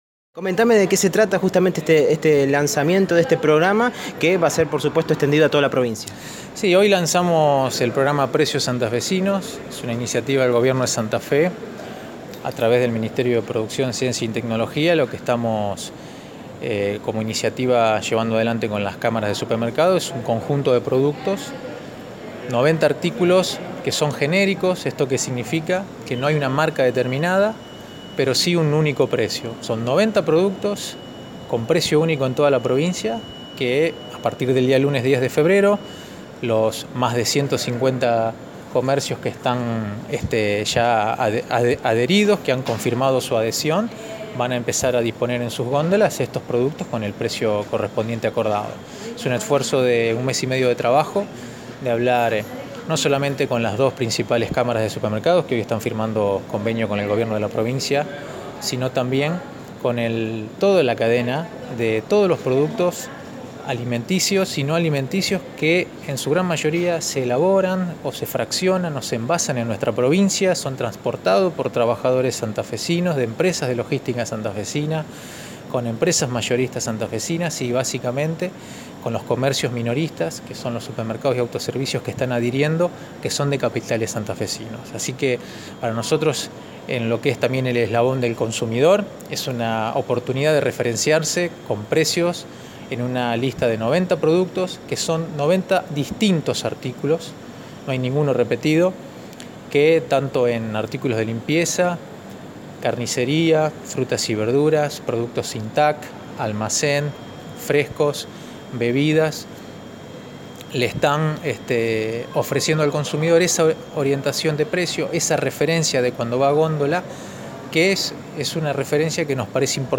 La palabra de Juan Marcos Aviano, secretario de Comercio Interior, en Radio EME: